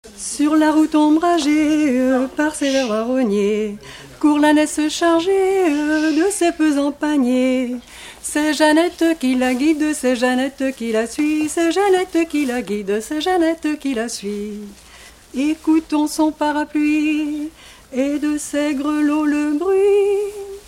Genre strophique
Regroupement de chanteurs locaux
Pièce musicale inédite